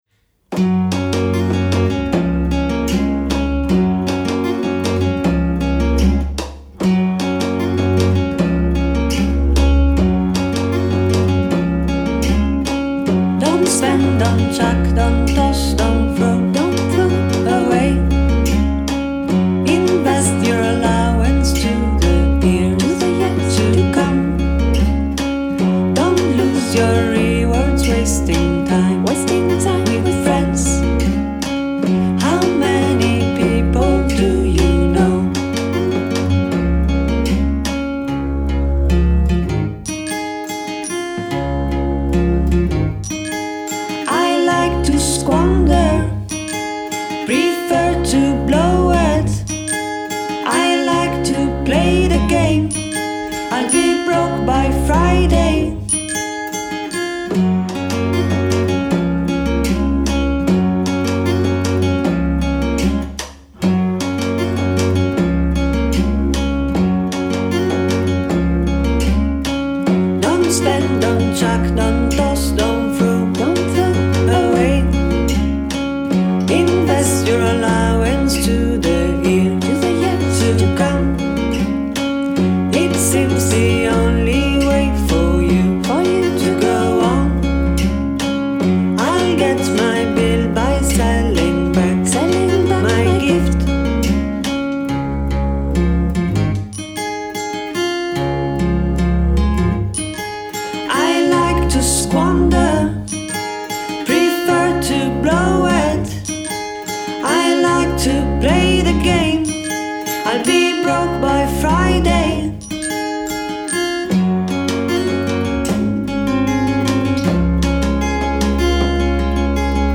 Canzoni di un folk affettuoso
il cui tratto principale è l'intreccio delle due voci